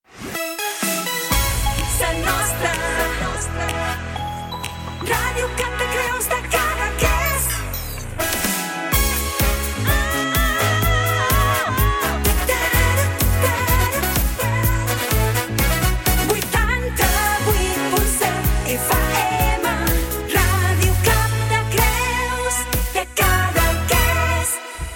Enregistrament extret del programa "Les Veus dels Pobles" de Ràdio Arrels.